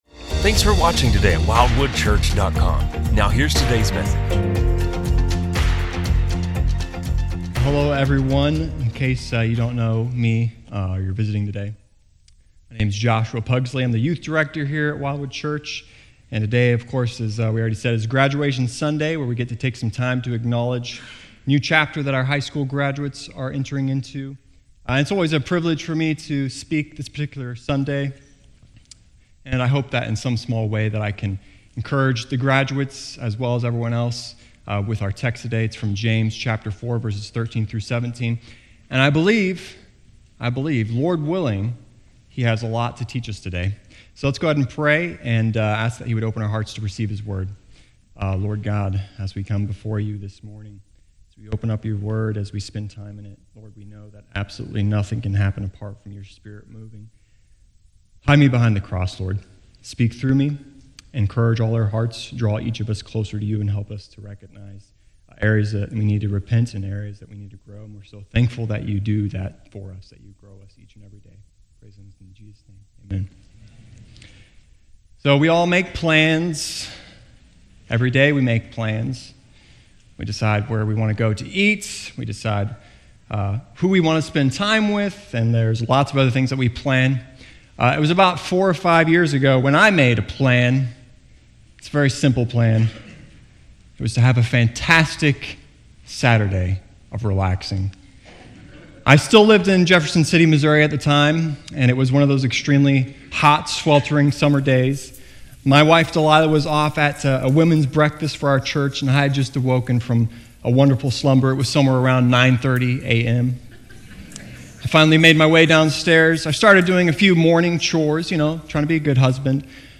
A message from the series "Wisdom From Above." We are not in control of our destiny as the world would have us believe. Rather God is sovereign over all aspects of our lives.